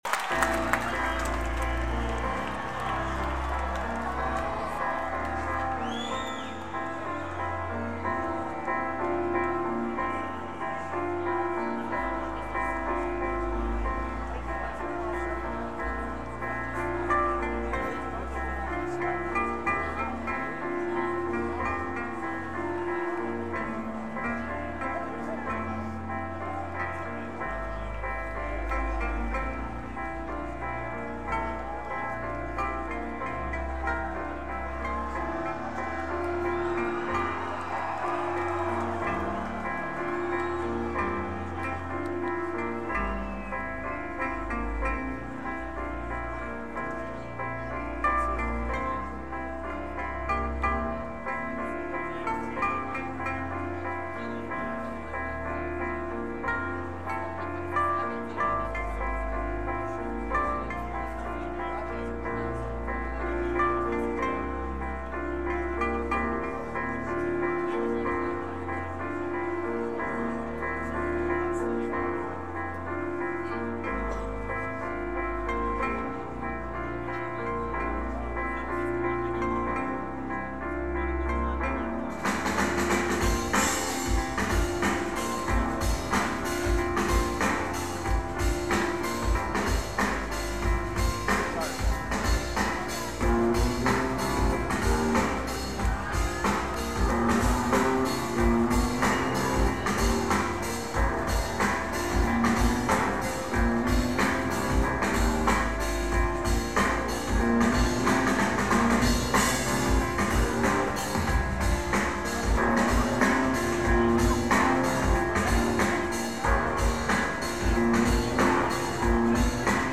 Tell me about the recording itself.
First Union Specturm Lineage: Audio - AUD (Schoeps MK41's + BB + Sony MZ-R3)